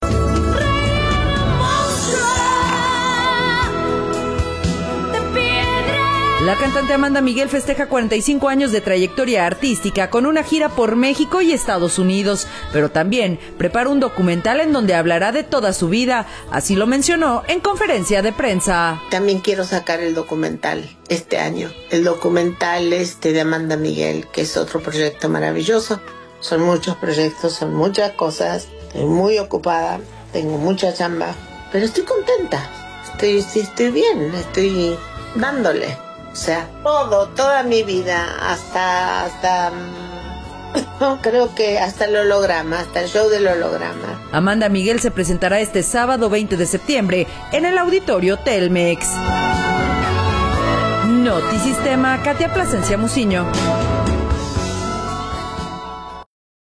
La cantante Amanda Miguel festeja 45 años de trayectoria artística con una gira por México y Estados Unidos, pero también prepara un documental en donde hablará de toda su vida, así lo mencionó en conferencia de prensa.